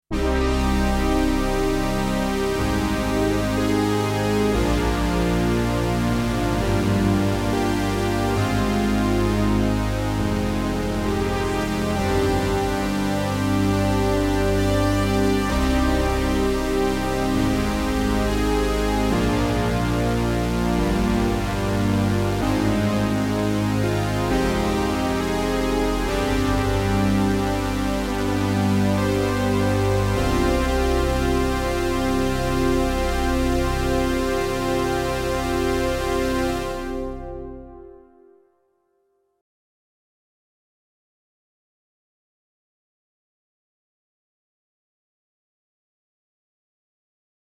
Sehr und genau so anders, dass sie sich, jedenfalls für meinen Geschmack, auch super ergänzen. Anhang anzeigen LayerP10undOB6.mp3